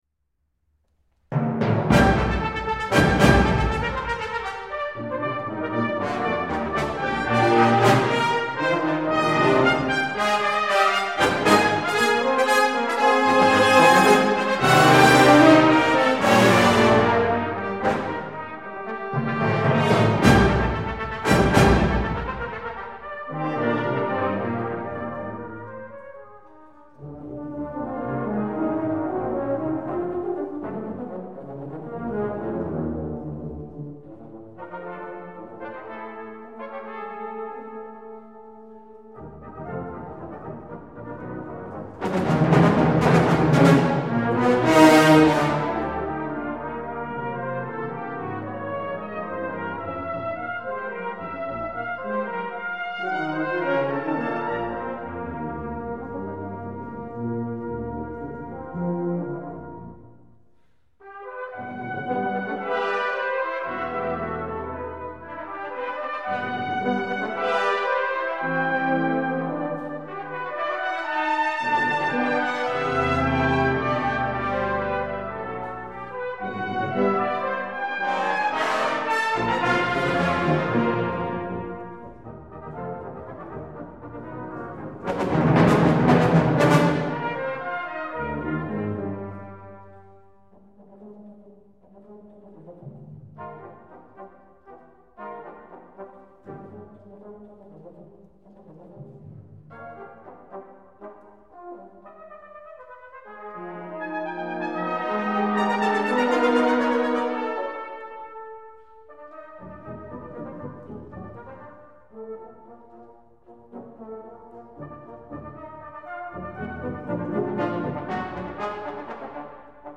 Very tuneful and some great sounds.